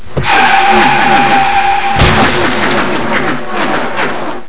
kpcrash.wav